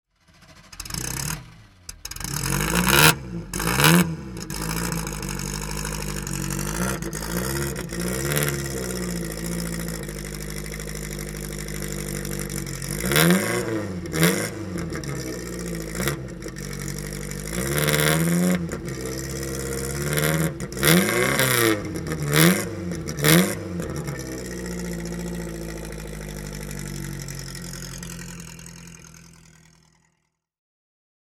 Fiat 500 C Topolino (1949) - Starten und Leerlauf